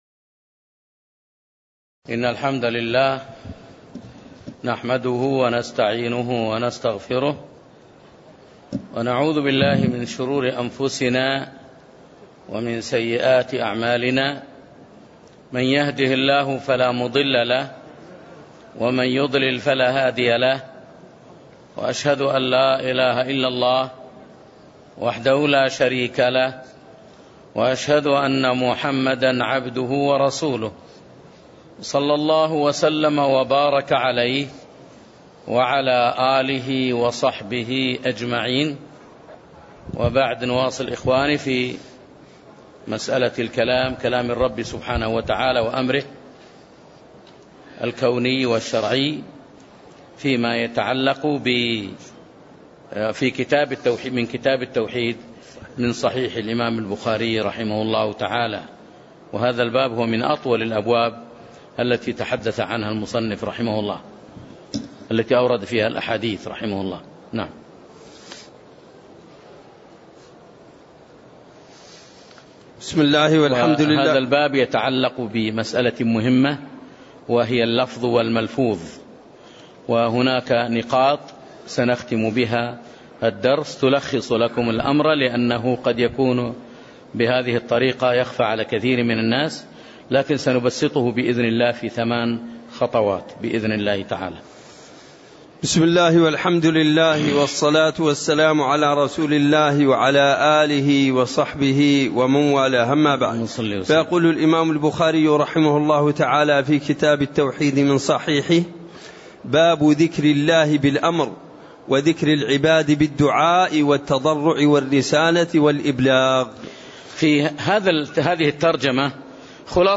تاريخ النشر ٢٣ محرم ١٤٣٦ هـ المكان: المسجد النبوي الشيخ